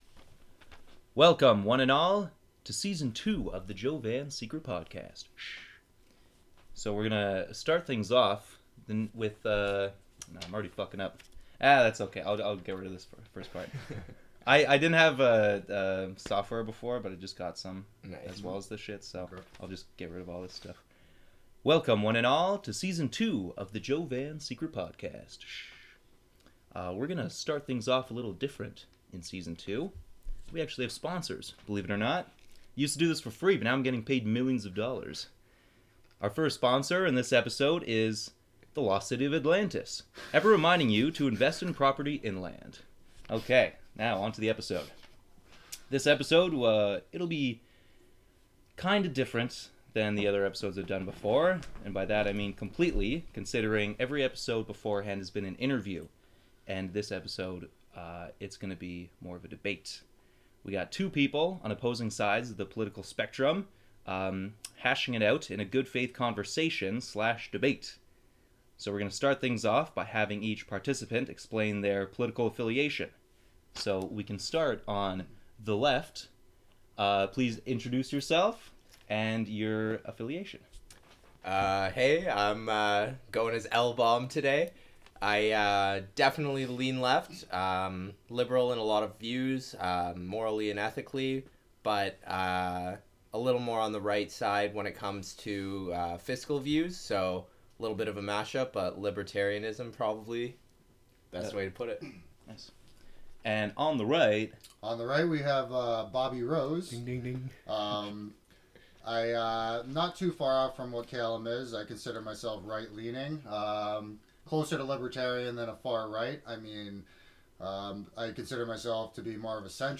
In the Secret Podcast #27, I bring in two boys to talk politics. We hit the topics of commercial drones, legal cannabis, nationalism, globalism, and gun freedom.